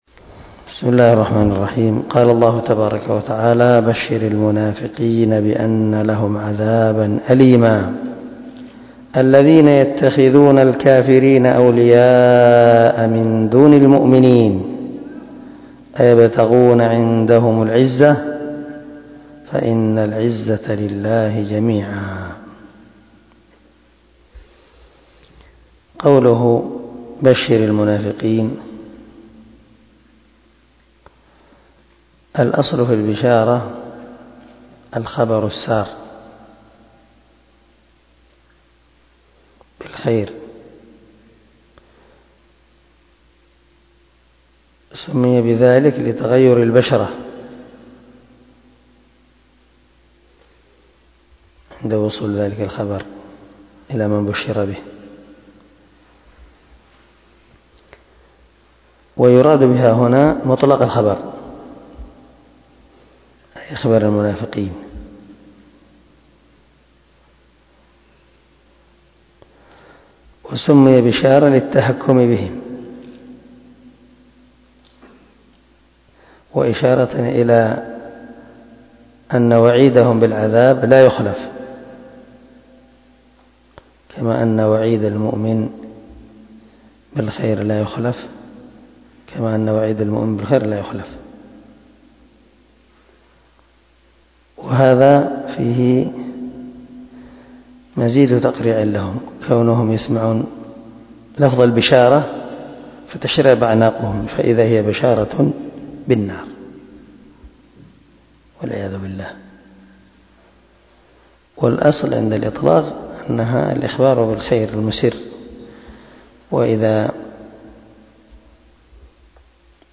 318الدرس 86 تفسير آية ( 138 – 139 ) من سورة النساء من تفسير القران الكريم مع قراءة لتفسير السعدي